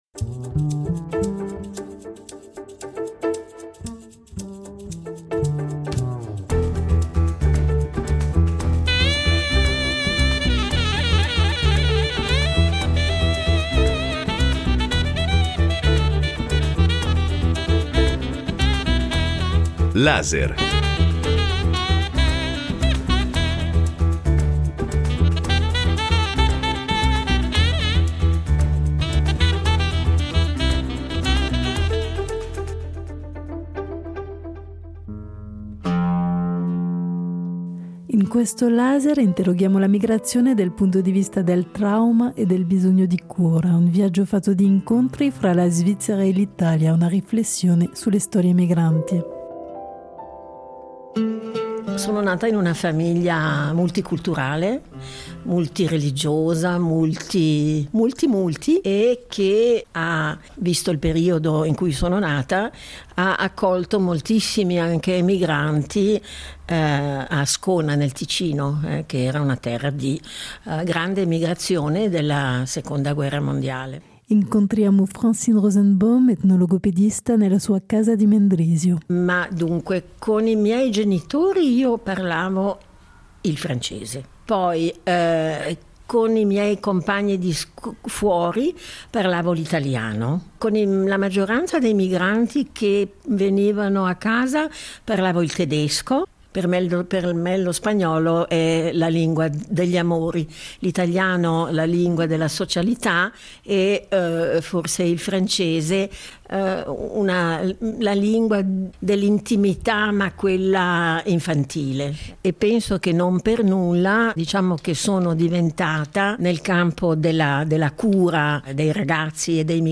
Questo documentario interroga la questione della migrazione attraverso la lente della salute mentale, restituendo un racconto polifonico fatto di voci professionali e testimonianze dirette.